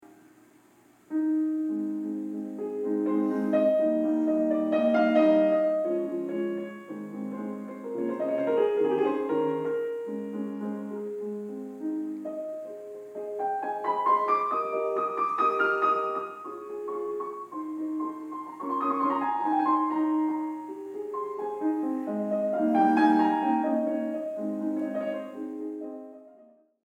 And now comes this wonderful melody. If you listen to the left hand it has the same accompaniment as the beginning of the whole sonata.